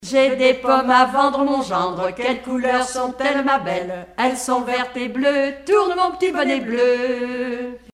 enfantine : jeu de balle
Regroupement de chanteurs du canton
Pièce musicale inédite